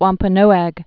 (wämpə-nōăg)